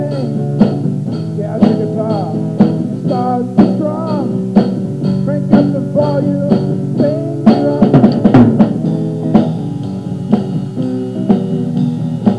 They are OK  sounding
Drums , vocals, harmonica .
Guitar, vocals, backing vocals.